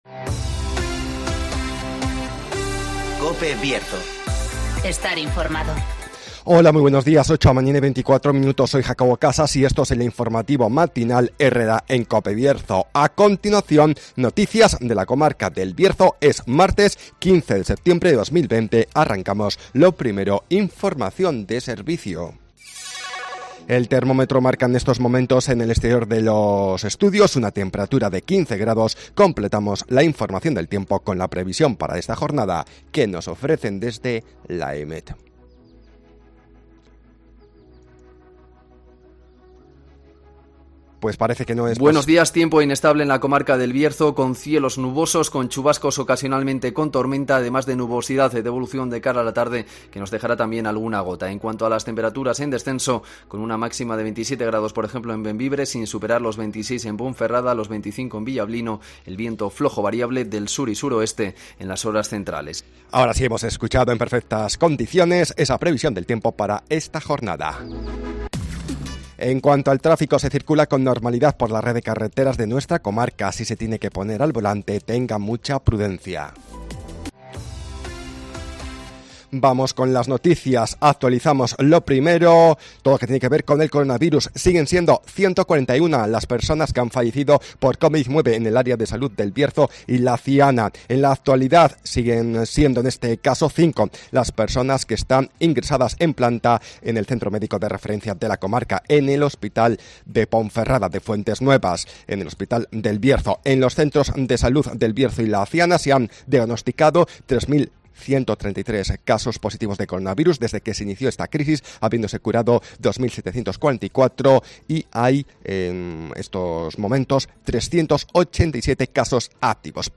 INFORMATIVOS
Conocemos las noticias de las últimas horas de nuestra comarca, con las voces de los protagonistas
-Palabras de Eduardo Morán, presidente de la Diputación Provincia de León